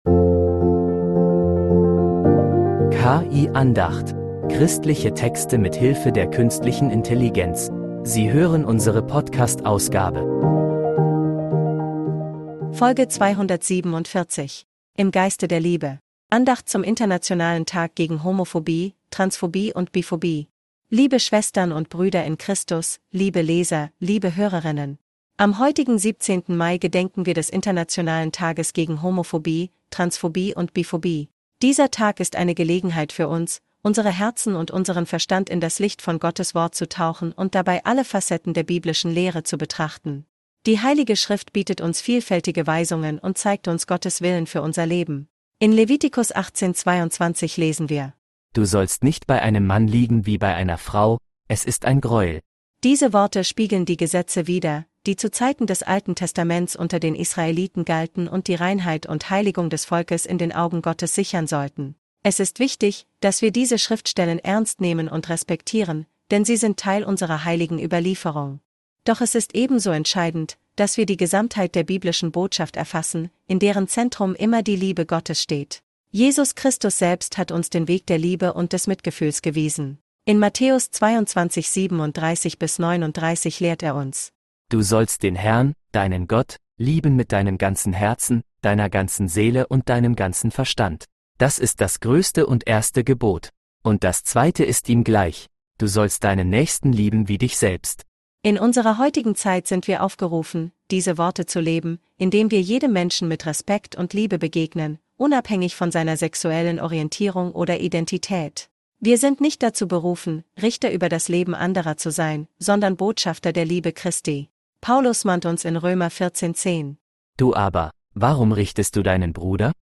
Andacht zum Internationalen Tag gegen Homophobie, Transphobie und Biphobie